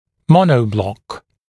[‘mɔnəuˌblɔk][‘моноуˌблок]моноблок